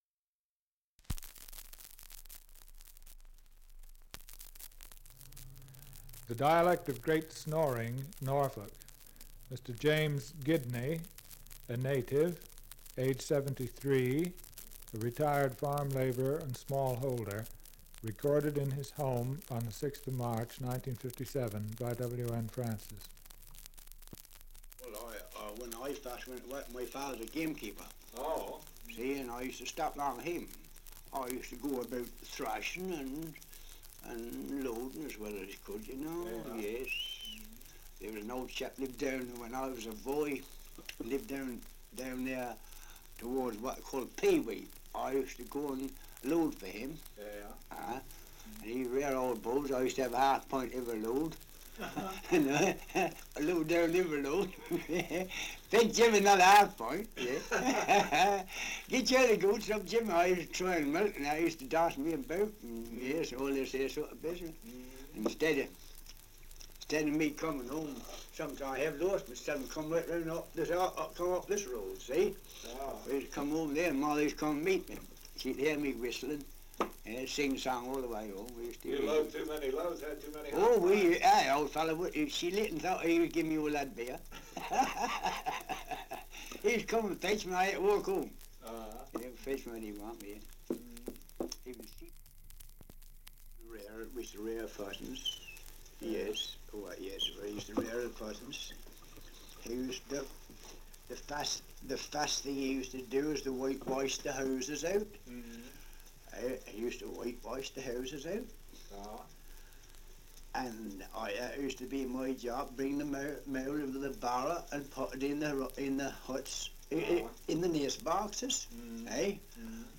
1 - Survey of English Dialects recording in Great Snoring, Norfolk
78 r.p.m., cellulose nitrate on aluminium